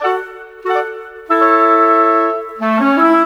Rock-Pop 06 Winds 02.wav